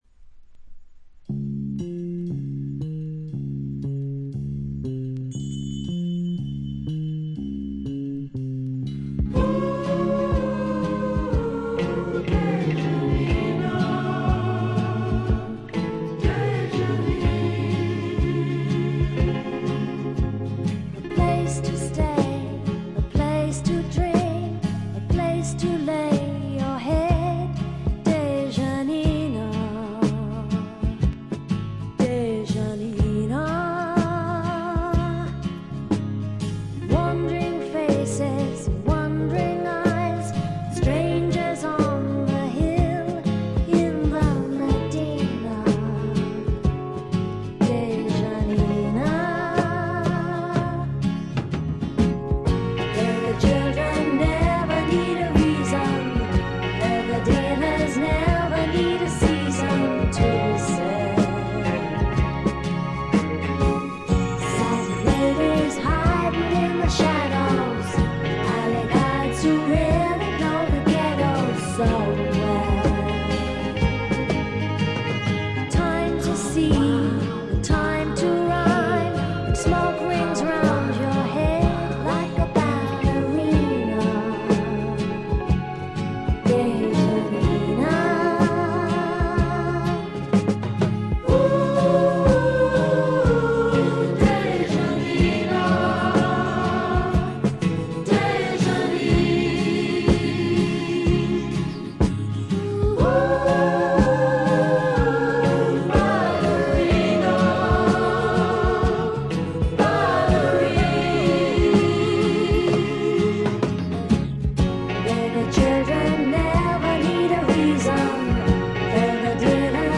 静音部で微細なチリプチのみでほとんどノイズ感無し。
試聴曲は現品からの取り込み音源です。